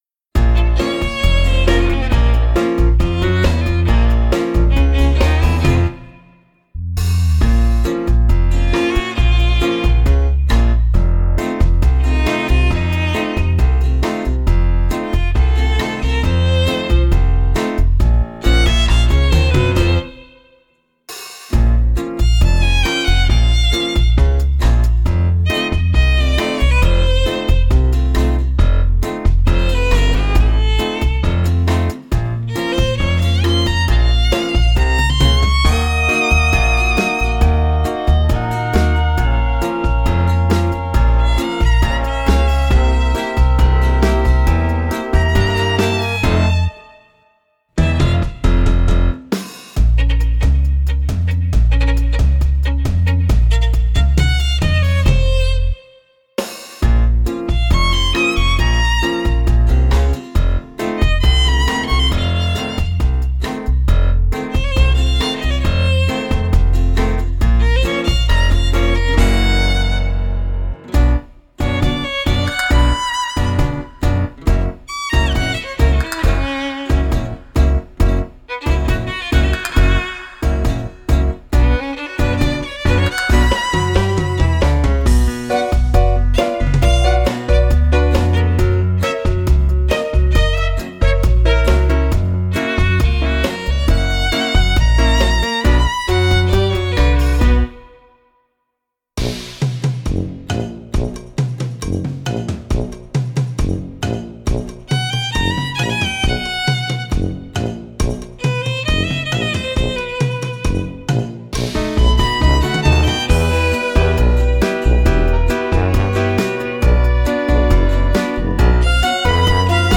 - instrumental